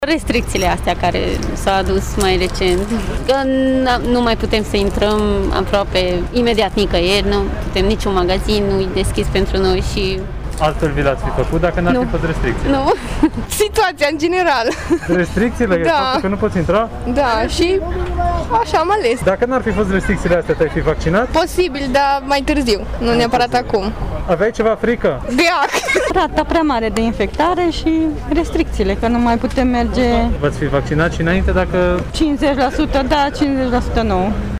La caravana au venit atât persoane vârstnice cât și tineri, care spun că motivul principal pentru care au ales să se vaccineze îl reprezintă restricțiile: